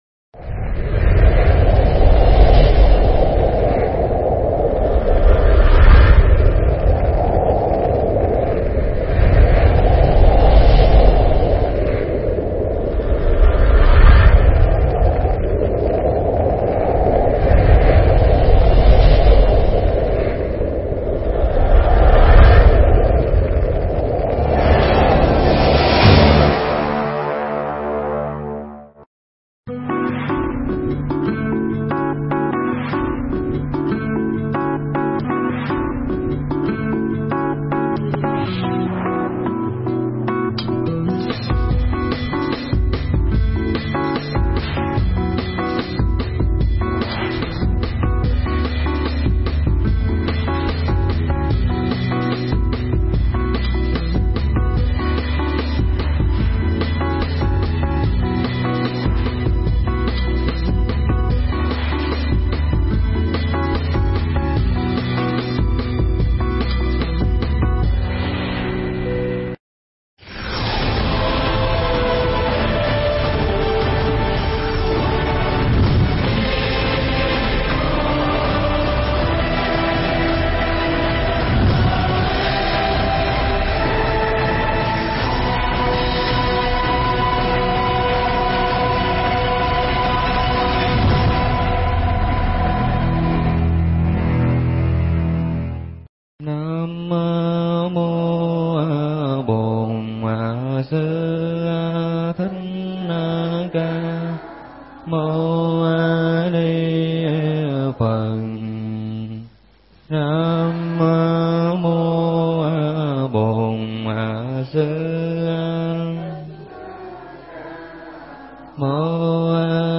Mp3 Bài pháp thoại Hóa giải nghiệp chướng do thầy Thích Nhật Từ Giảng tại nhà hàng chay Mandala, Quận 1, ngày 04 tháng 01 năm 2014